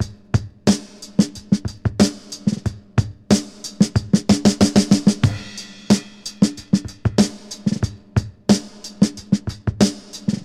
• 92 Bpm Modern Drum Loop Sample G# Key.wav
Free breakbeat - kick tuned to the G# note. Loudest frequency: 1187Hz
92-bpm-modern-drum-loop-sample-g-sharp-key-yoe.wav